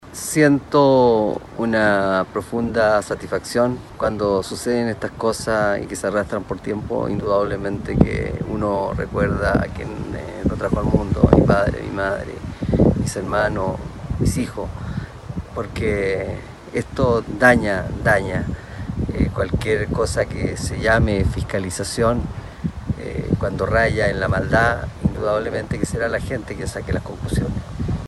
En ese marco, Ramón Bahamonde se mostró emocionado y señaló que situaciones como estas solo dañan lo que se conoce como fiscalización.